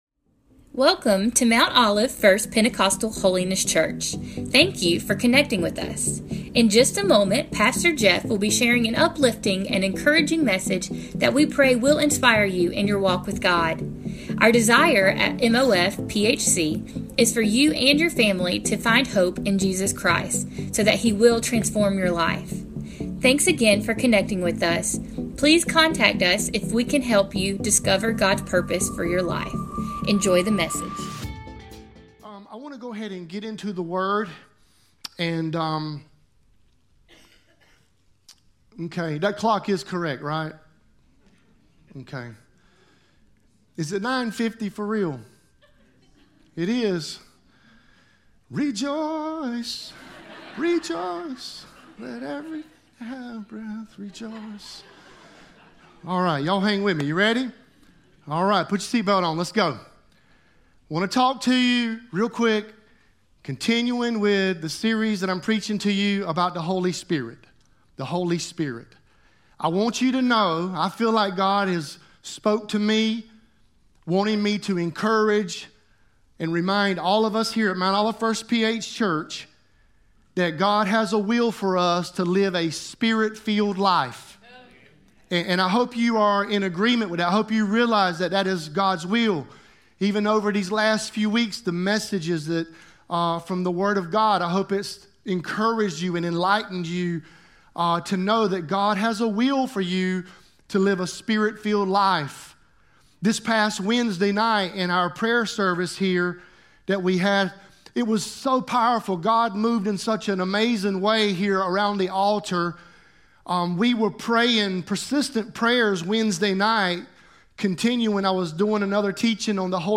Mount Olive First PH Sermons